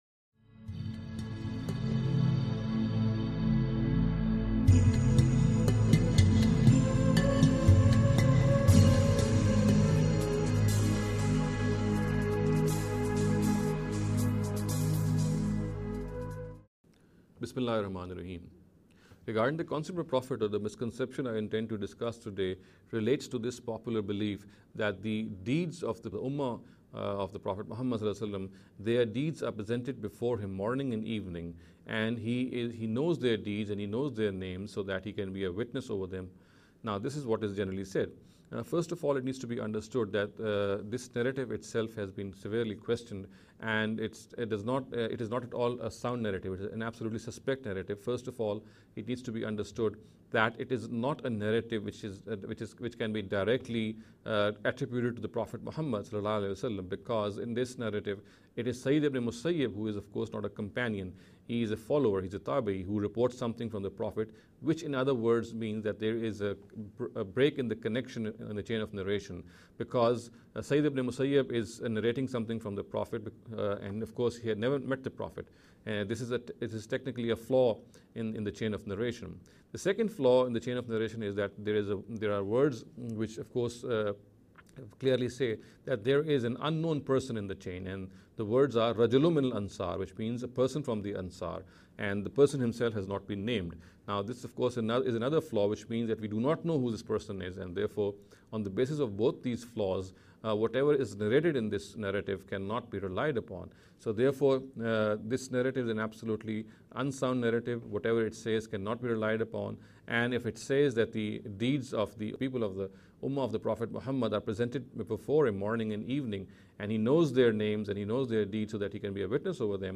This lecture series will deal with some misconception regarding the Concept of Prophethood.